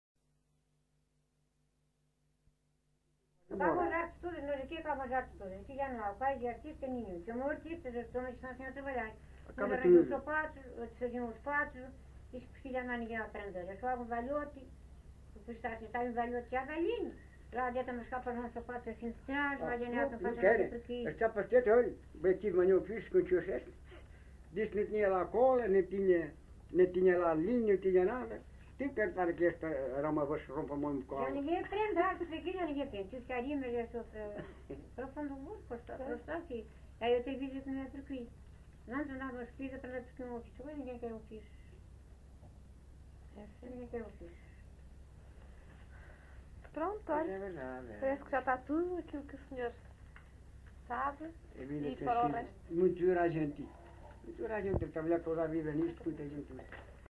LocalidadeMonsanto (Idanha-a-Nova, Castelo Branco)